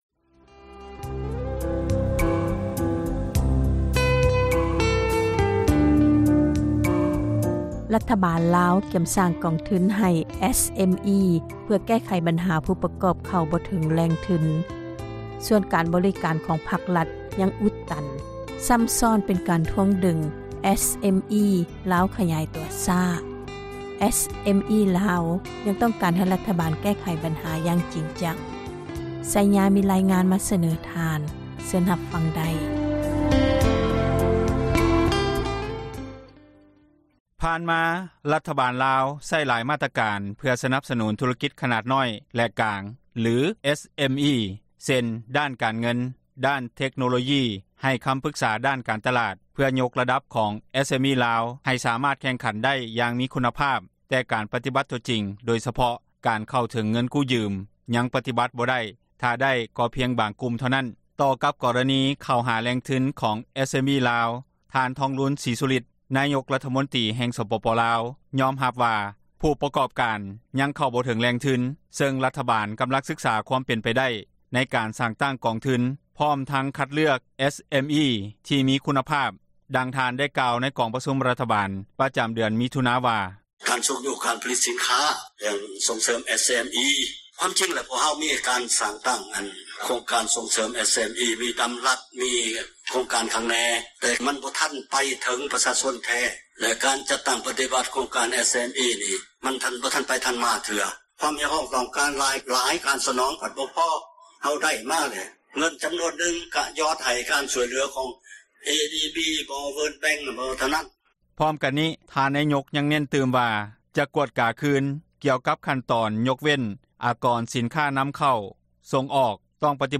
ດັ່ງທ່ານ ໄດ້ກ່າວໃນກອງປະຊຸມ ຂອງຣັຖບານ ປະຈຳເດືອນ ມິຖຸນາ ວ່າ: